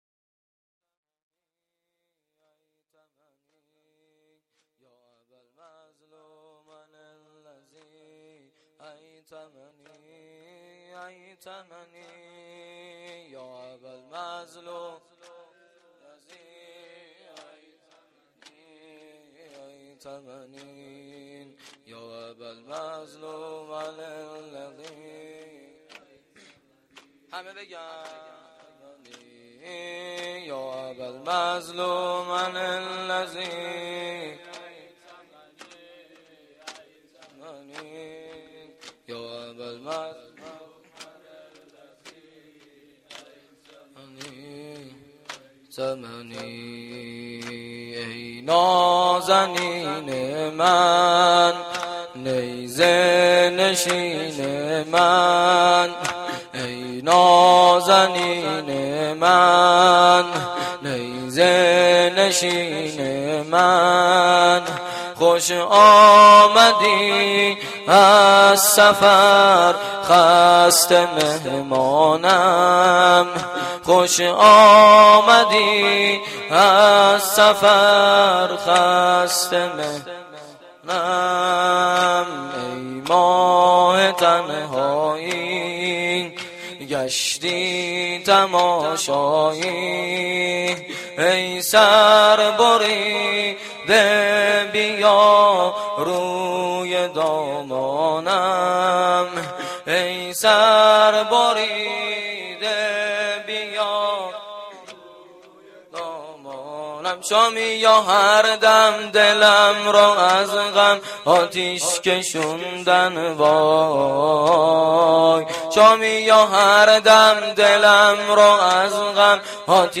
روضه العباس